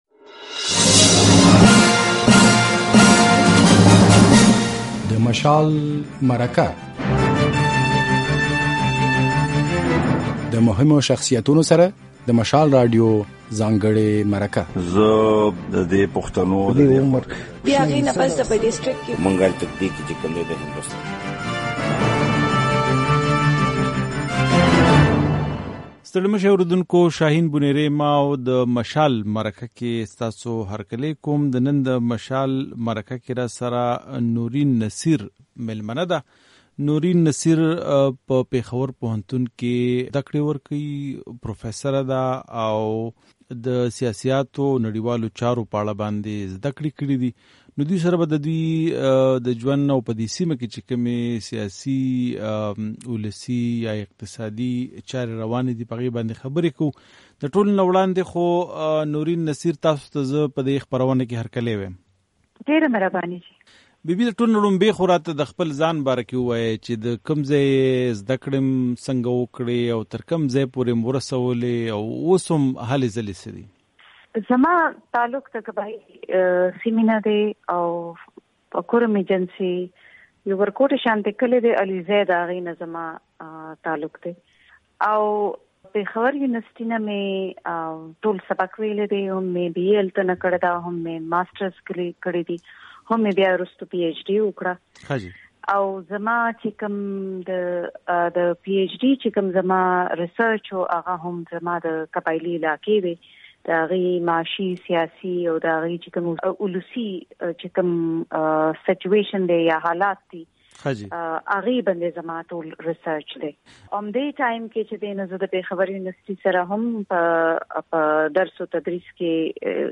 مشال مرکه